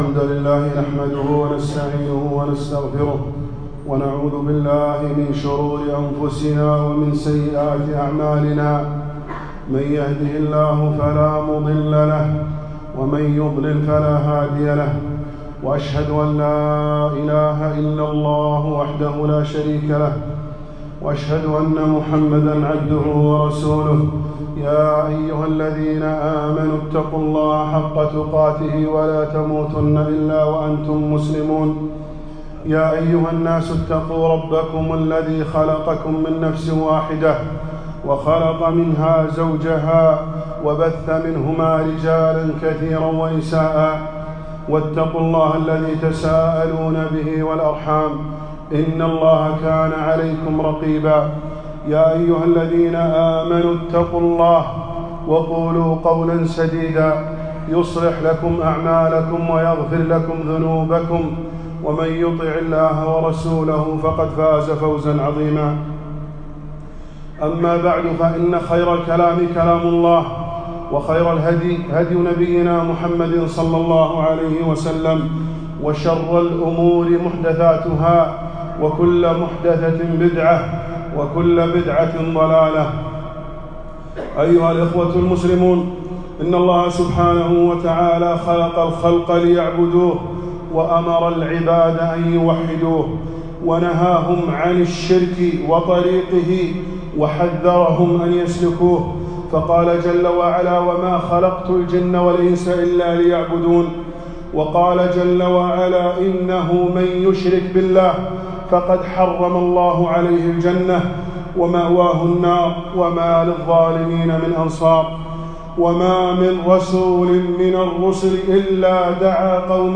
خطبة - إِنَّ اللَّهَ لَا يَغْفِرُ أَن يُشْرَكَ بِه